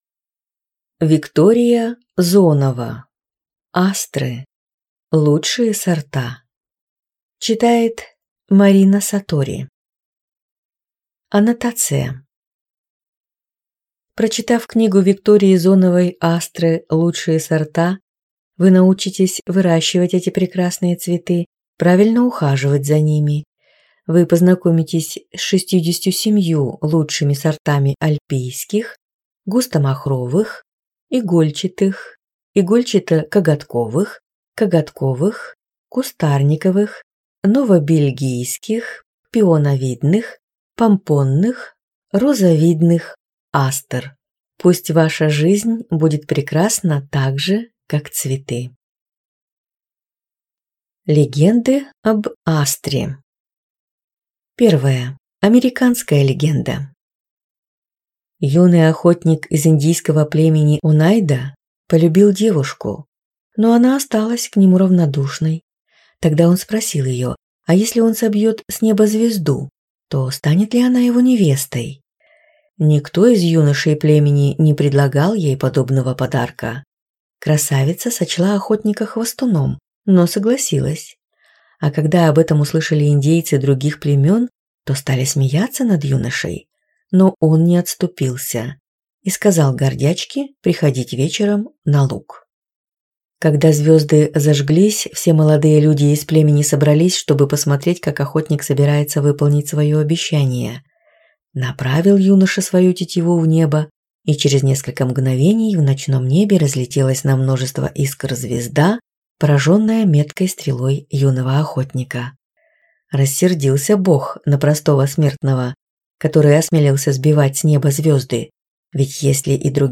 Аудиокнига Астры. Лучшие сорта | Библиотека аудиокниг